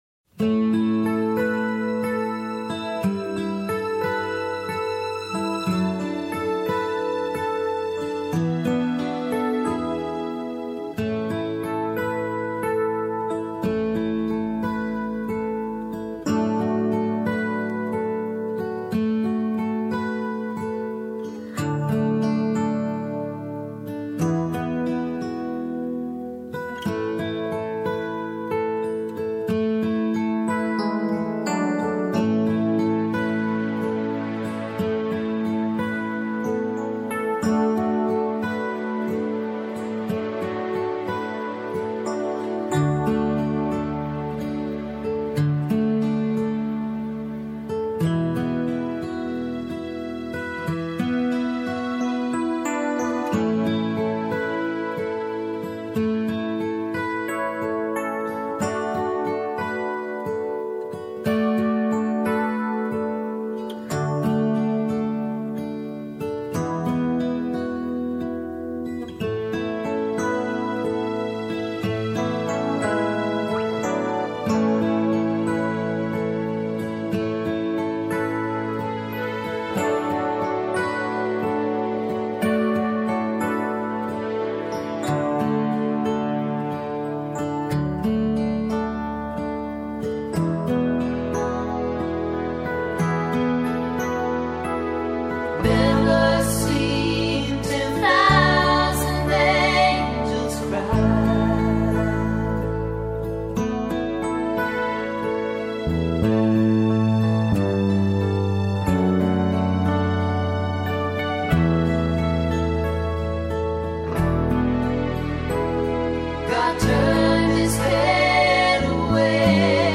Original Key with BV